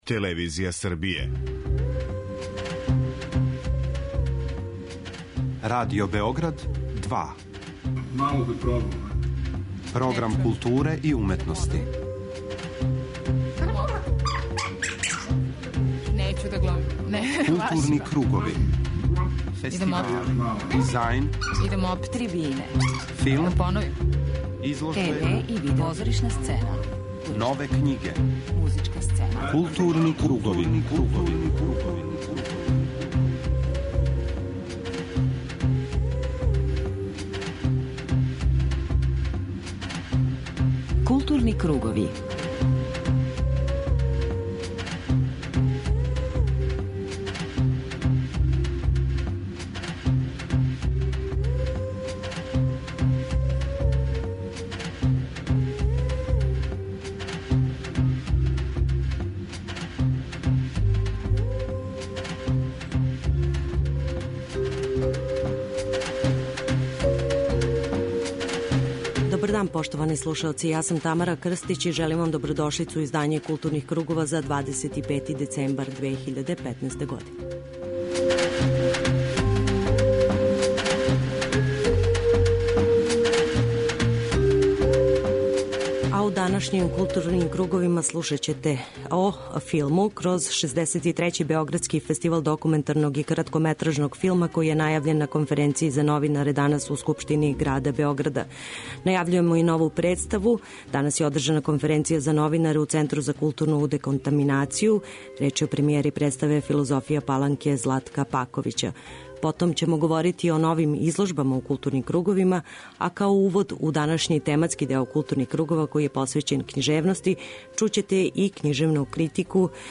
преузми : 41.11 MB Културни кругови Autor: Група аутора Централна културно-уметничка емисија Радио Београда 2.